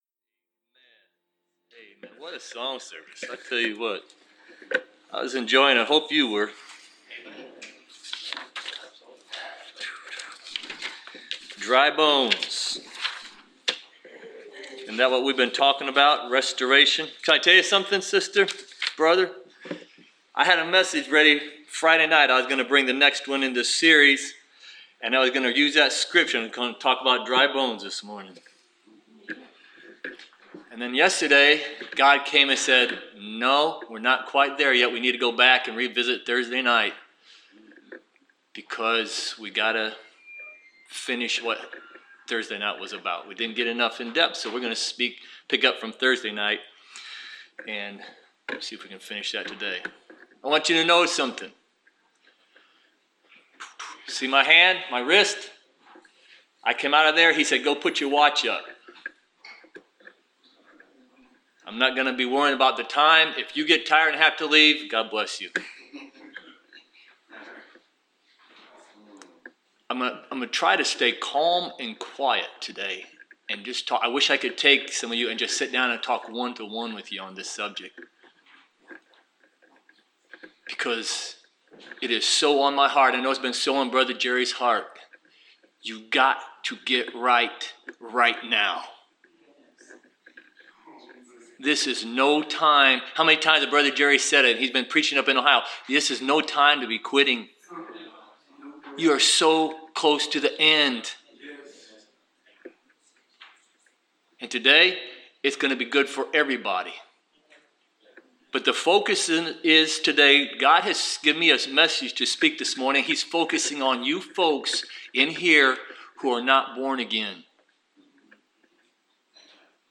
Preached August 27, 2017.